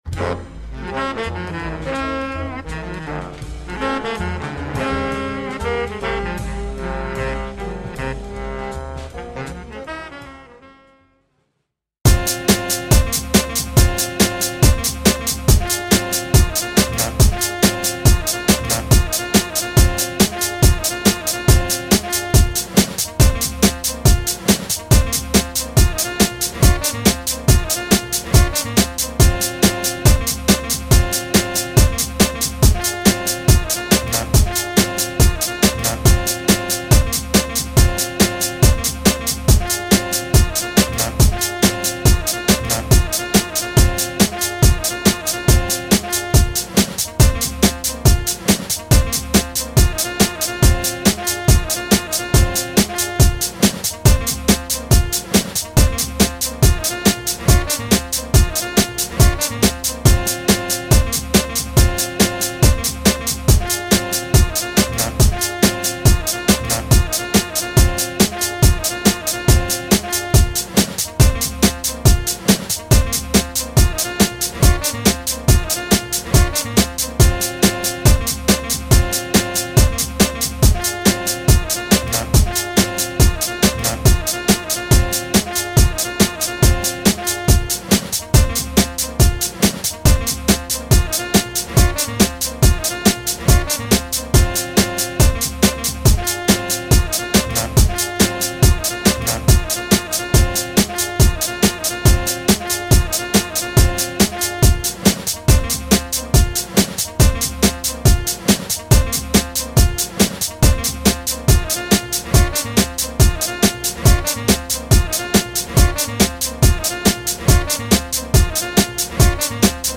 컷앤페이스트를 했습니다 나름의 ㅋㅋㅋㅋㅋㅋㅋ
제 나름대로 듣다보니....너무 한음이 반복되는게 많은거같아서...조금더 패턴을 넣어서 수정해봤어요 ㅎㅎ;;
오 좀 신선한 멜로디 구성이네요 ㅋ